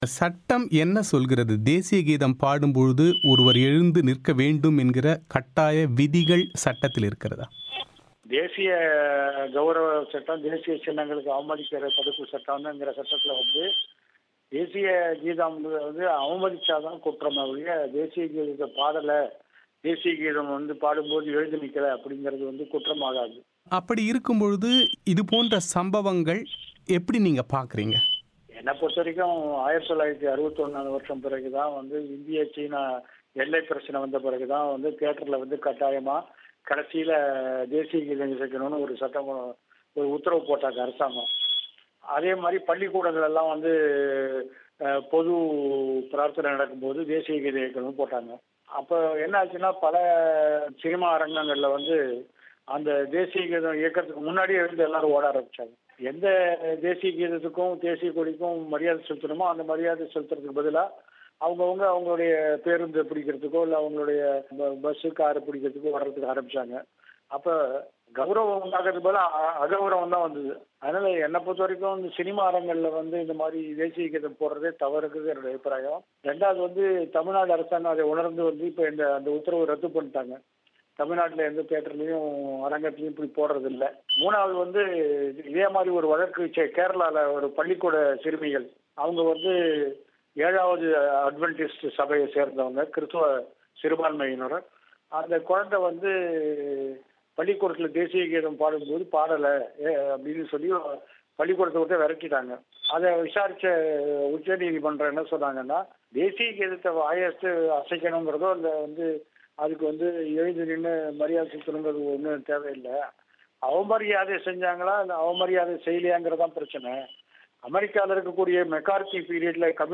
இந்த சர்ச்சை குறித்து பிபிசி தமிழோசைக்கு செவ்வியளித்த முன்னாள் சென்னை உயர்நீதிமன்ற நீதிபதி கே சந்துரு தேசிய கீதம் பாடும்போது எழுந்து நிற்காதது குற்றமல்ல என்று கூறினார்.